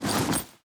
Open Inventory Bag A.wav